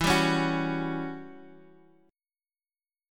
Em6add9 Chord
Listen to Em6add9 strummed